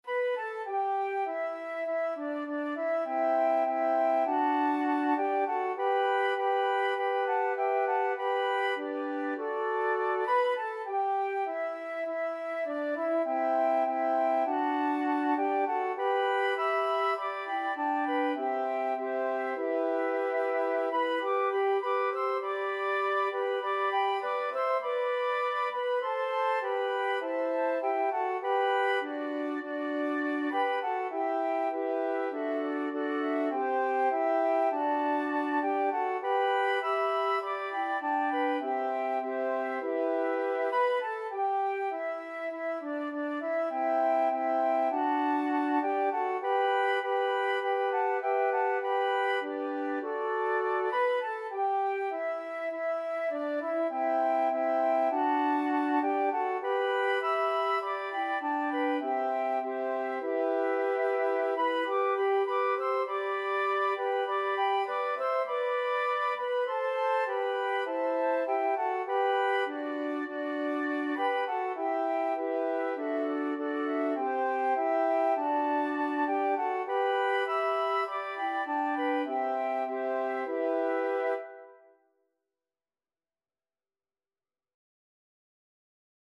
Flute 1Flute 2Flute 3Alto Flute
4/4 (View more 4/4 Music)
Moderato